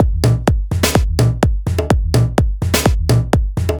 • groovy house kick and snare loop.wav
groovy_house_kick_and_snare_loop_Sq8.wav